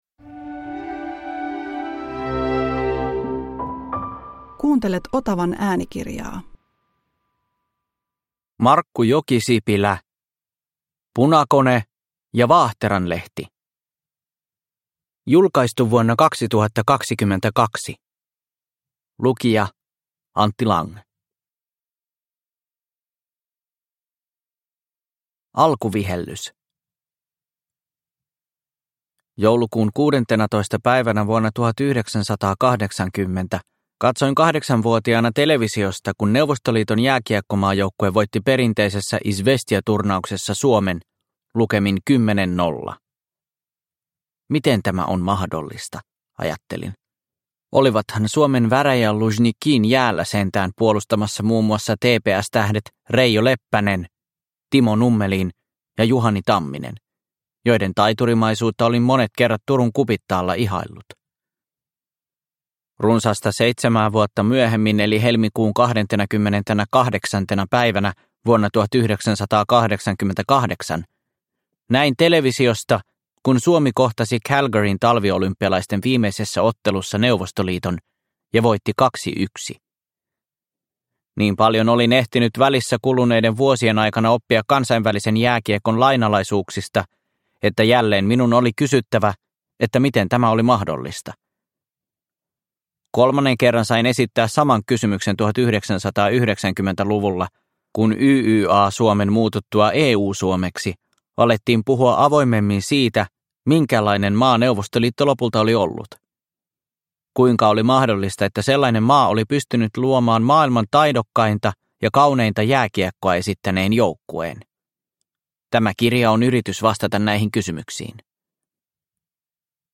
Punakone ja vaahteranlehti – Ljudbok – Laddas ner